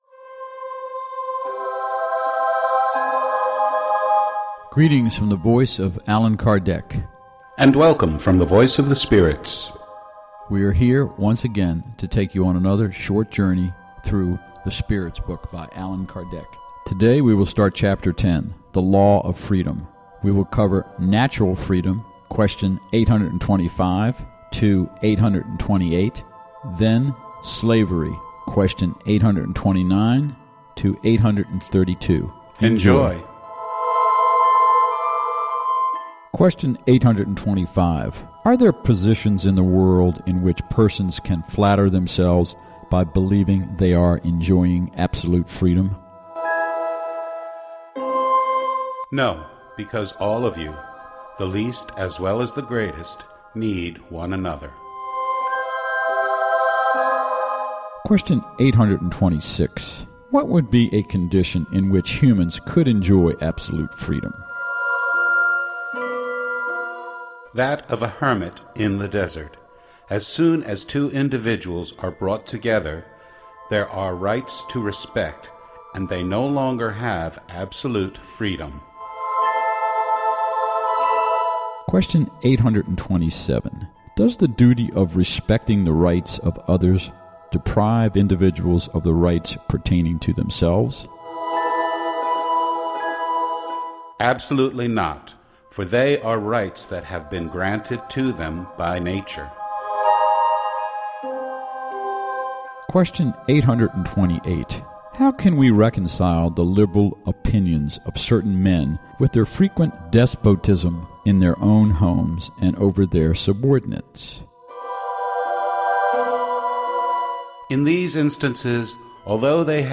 Now listen to its 1,019 questions and answers, which have been broadcast every Sunday at Spiritist Awareness Program at Kardec Radio.